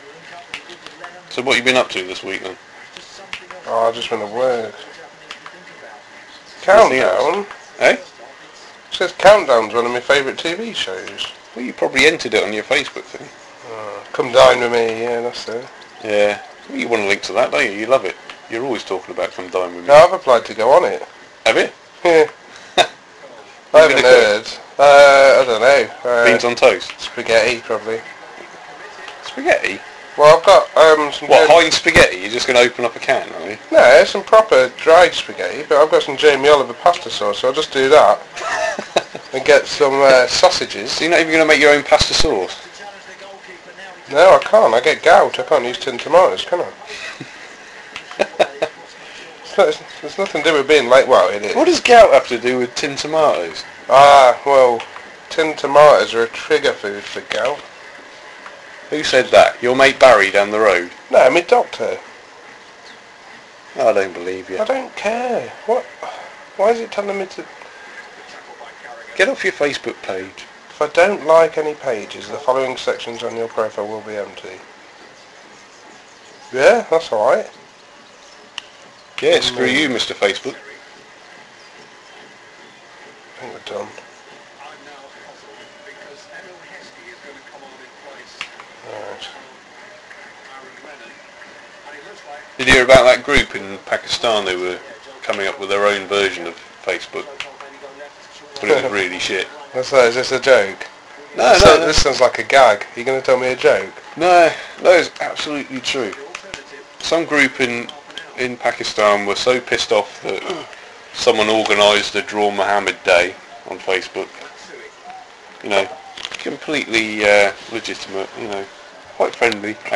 WARNING: Some Strong language and partial nudity